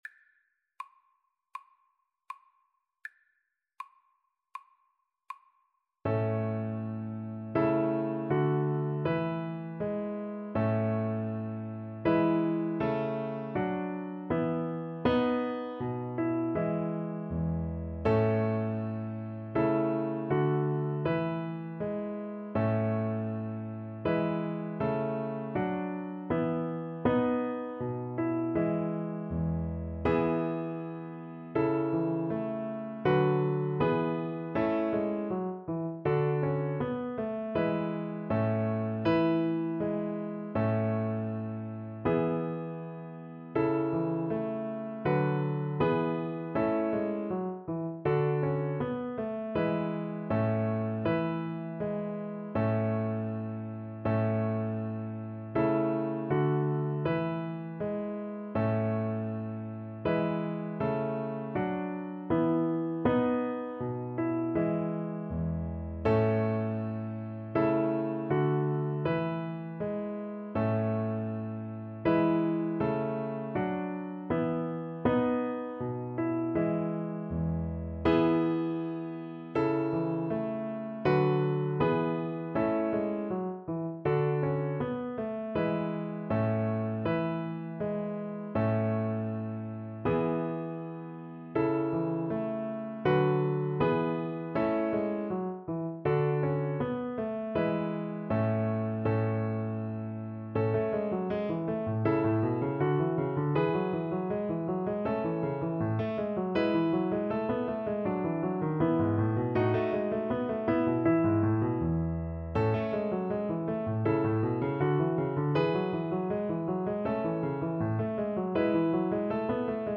4/4 (View more 4/4 Music)
Allegretto =80
Classical (View more Classical Violin Music)